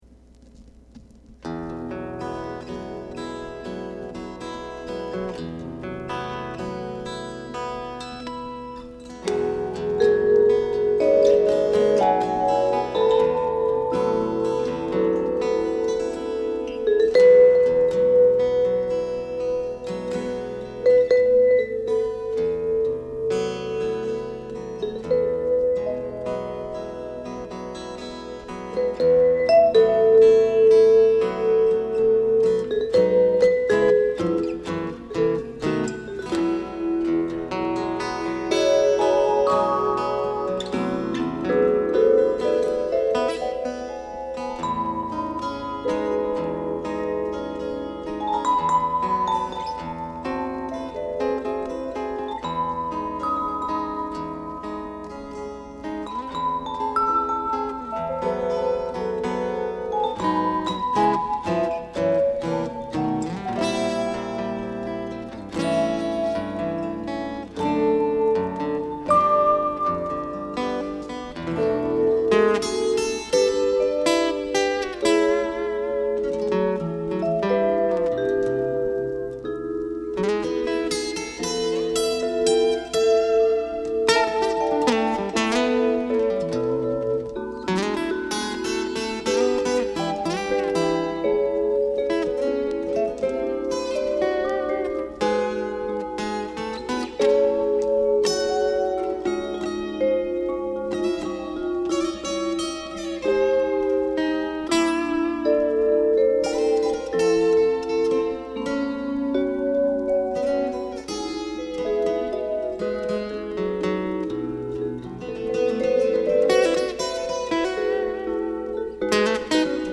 Acoustic Jazz / Guitar Jacket
ニューヨークのパワー・ステーションと東京・日本コロムビアスタジオの2か所で収録。
ギターとヴァイブが静かに絡み合い、空白の多い音空間が広がる。